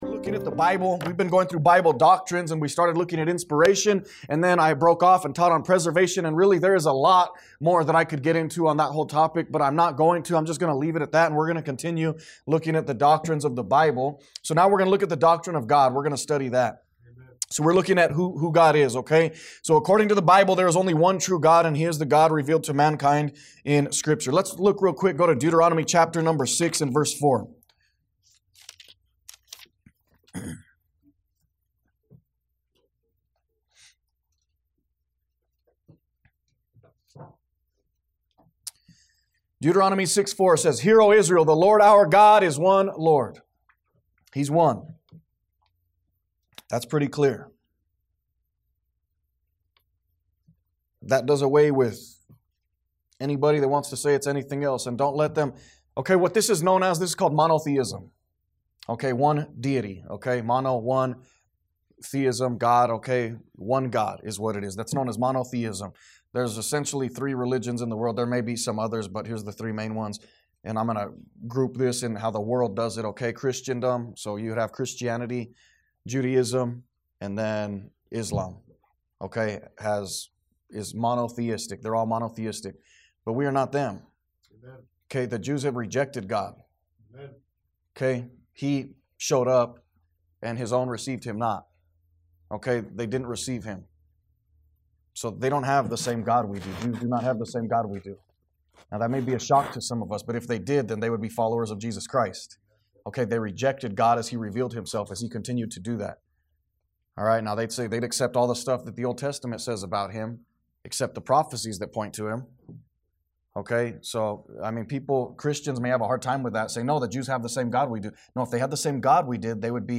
A message from the series "Doctrine of The Bible."